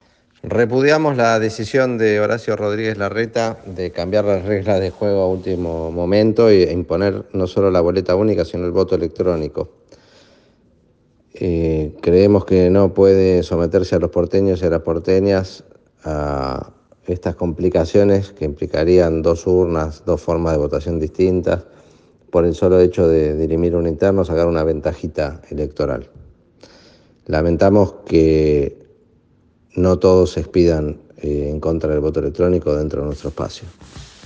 El Senador nacional y dirigente de la Ciudad de Buenos Aires, Mariano Recalde, habló en exclusiva con NCN y se refirió a la decisión del Jefe de Gobierno Porteño, Horacio Rodríguez Larreta, de utilizar la boleta única.